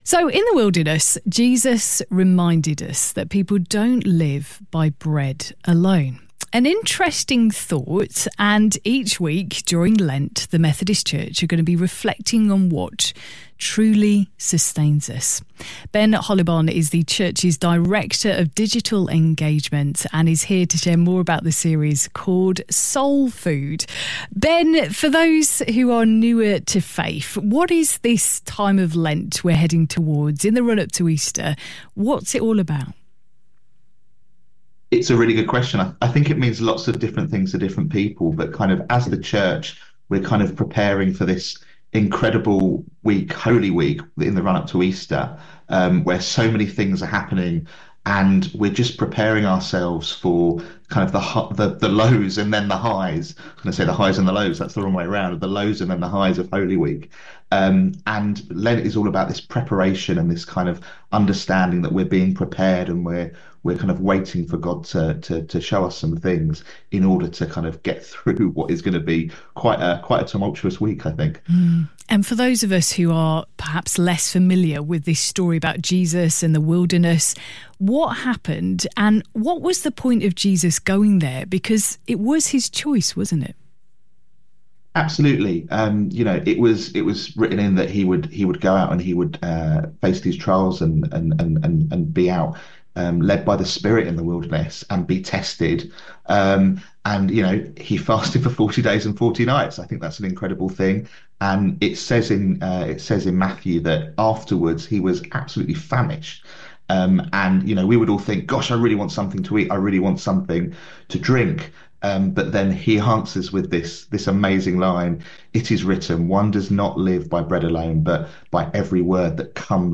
UCB-Soul-Food-interview.mp3